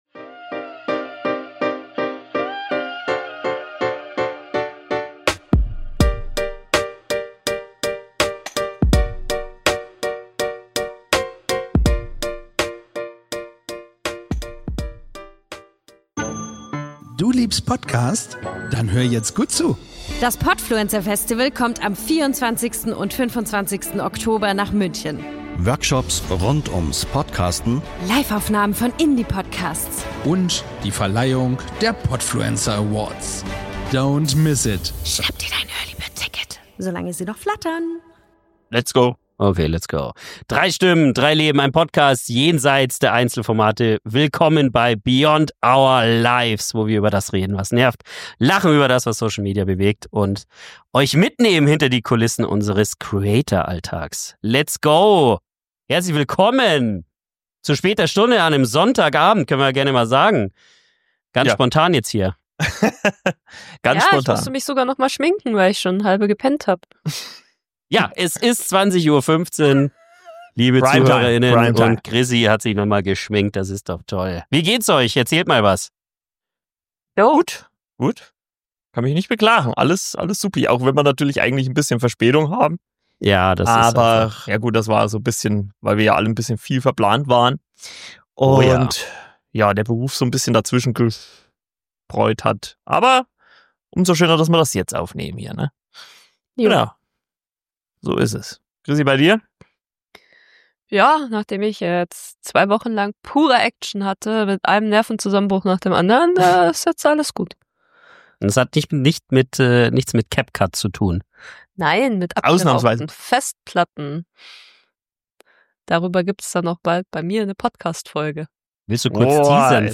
Drei Hosts im Gespräch